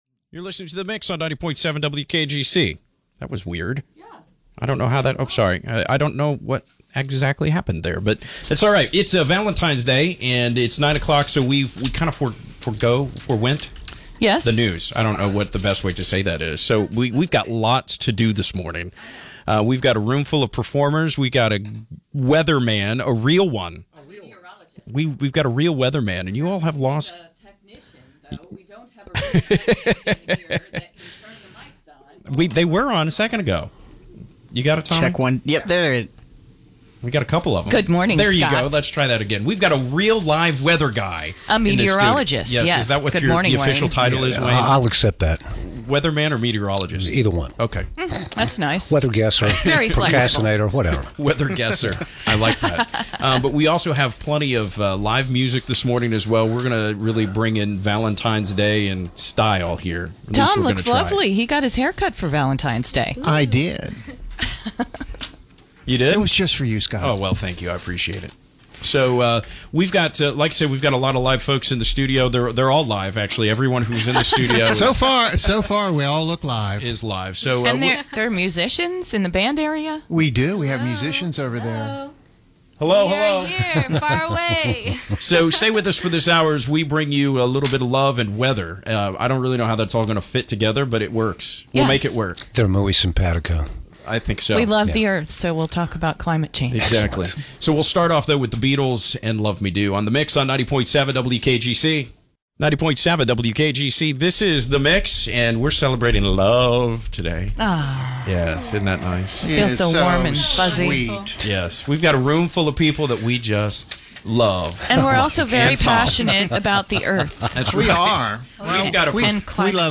The Morning Mix crew bring in the Day of Love our own way with live music from multiple guests, and some of our own, a vinyl play of the day, and more!